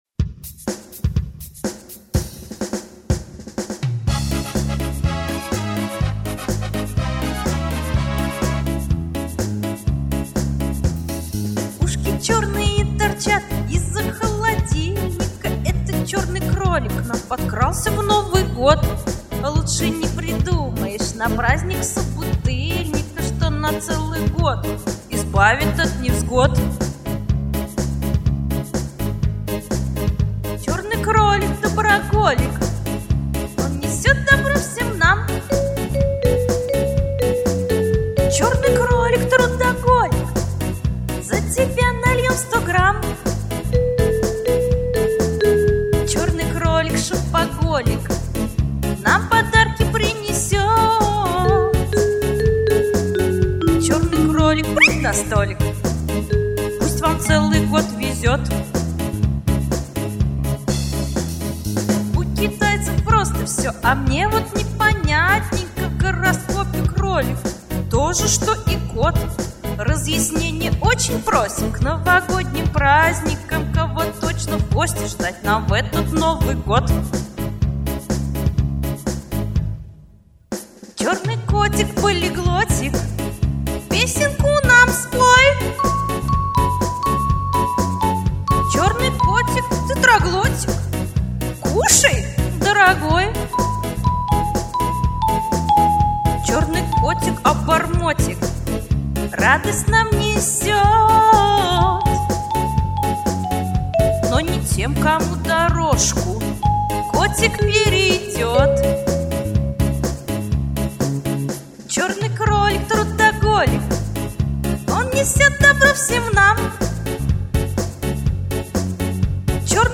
Жанр: Русский поп-шансон